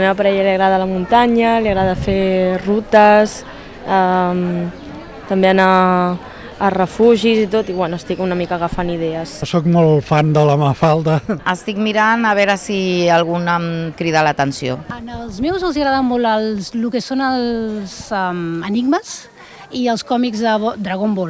Fins allà s'hi ha desplaçat la unitat mòbil de Ràdio Argentona per copsar l'ambient d'una jornada marcada pel bon temps, per un ambient molt animat i amb força persones remenant llibres per fer un bon regal.